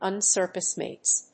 音節un・cir・cum・cised 発音記号・読み方
/`ʌnsˈɚːkəmsὰɪzd(米国英語)/